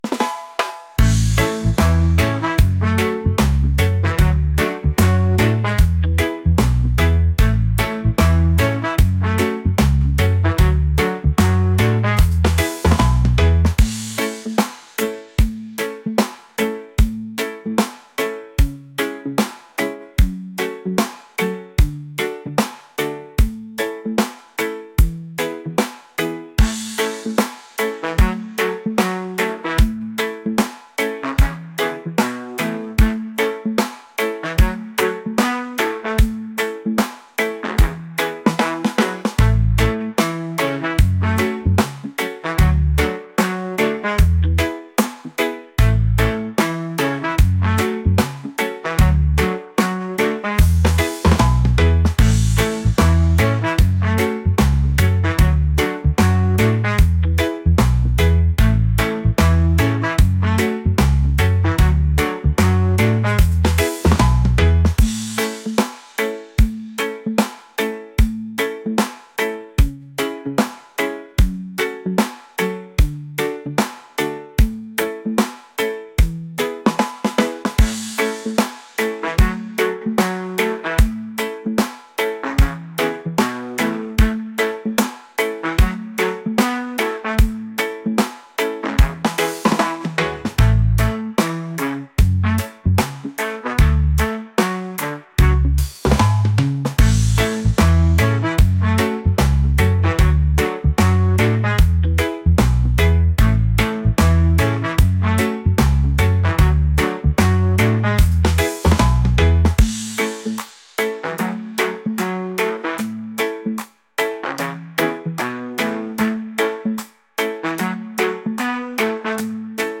laid-back | reggae | ska